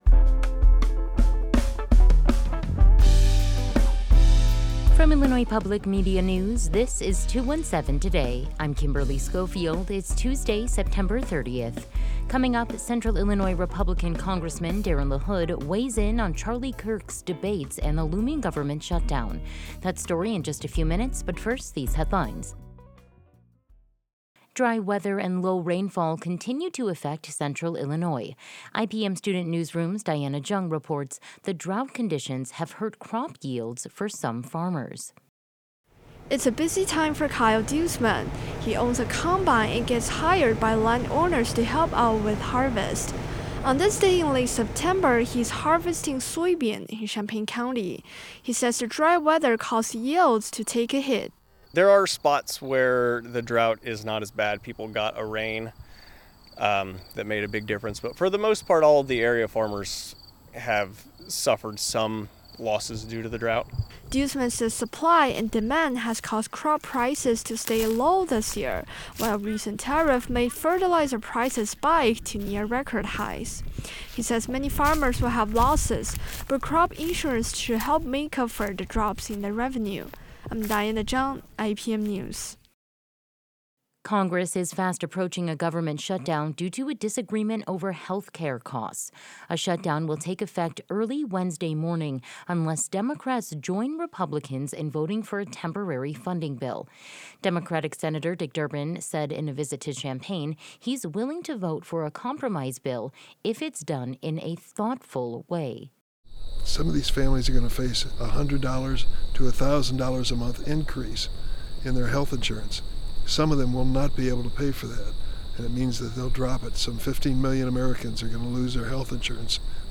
In today’s deep dive, Central Illinois Republican congressman Darin LaHood weighs in on Charlie Kirk’s debates and the looming government shutdown.